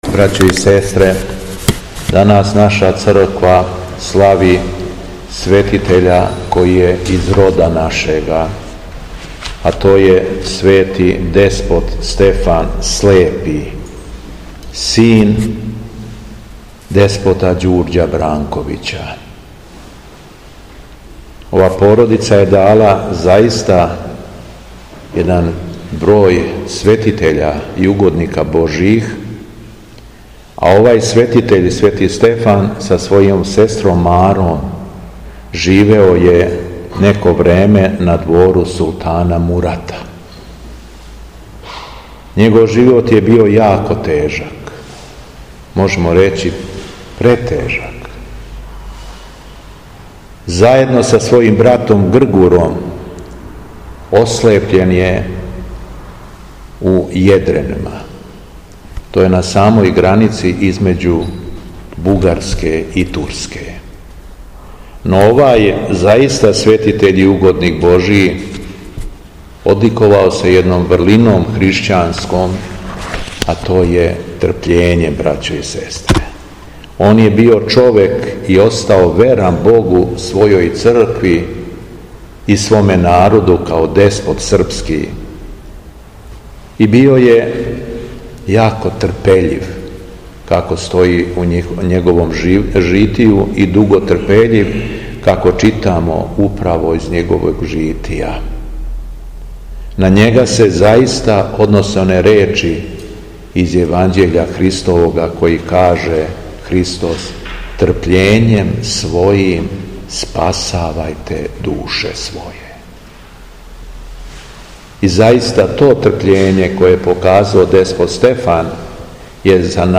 Беседа Његовог Високопреосвештенства Митрополита шумадијског г. Јована
После прочитаног јеванђелског зачала Високопреосвећени се обратио верном народу надахнутом беседом: